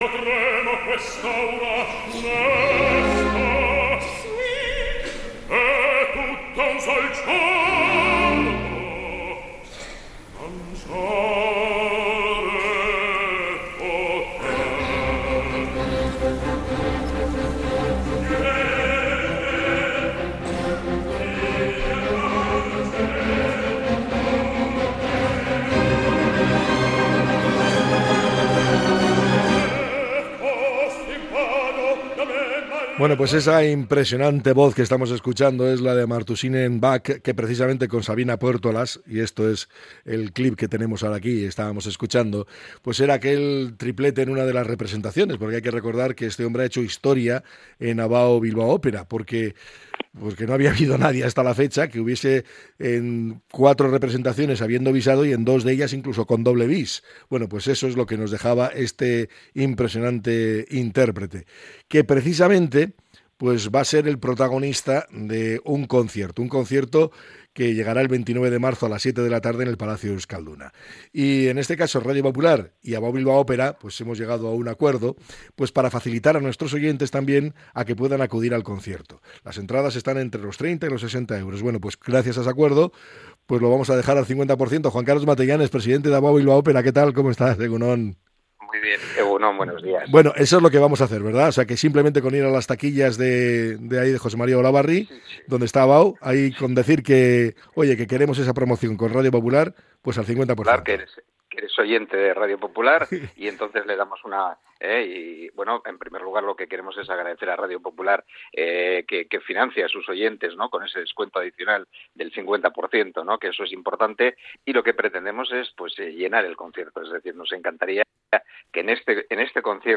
Durante la entrevista, ha destacado la importancia de hacer accesible la ópera a más público y la oportunidad única que supone poder disfrutar de un barítono de la talla de Amartuvshin Enkhbat a un precio reducido.